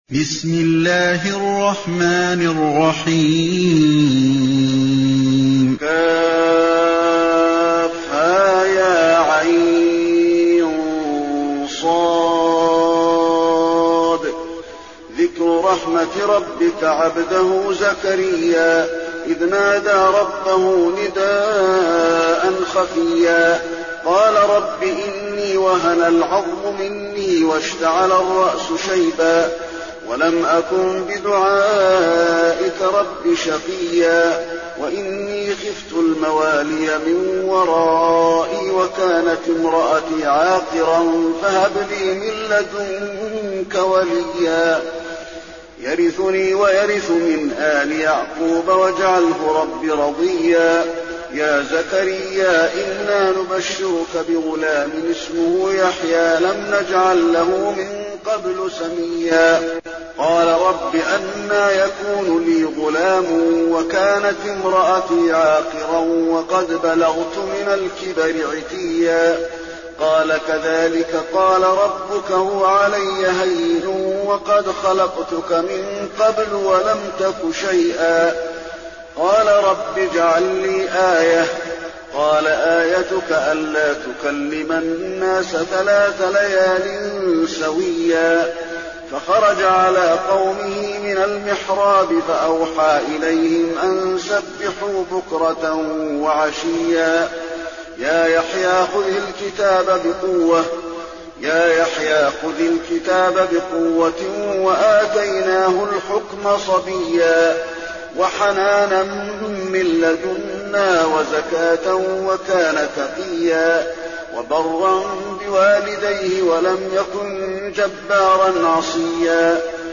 المكان: المسجد النبوي مريم